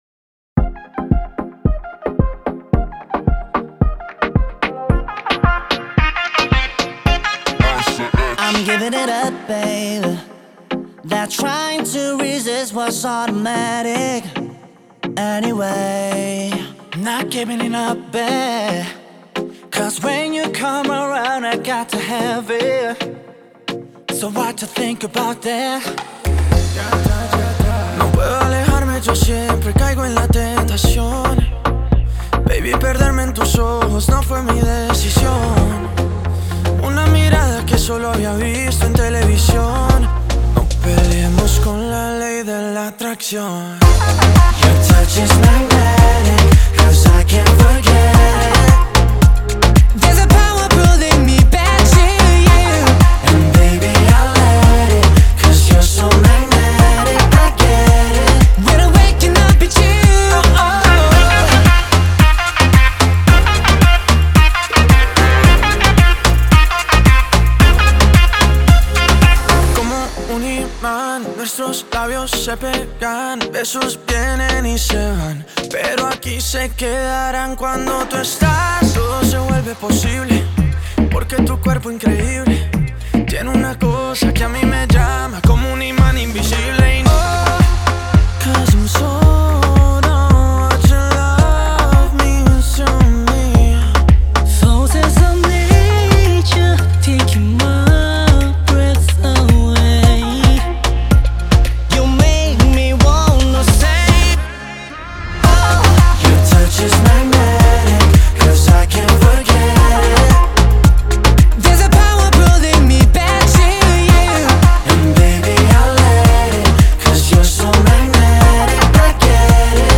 Латиноамериканская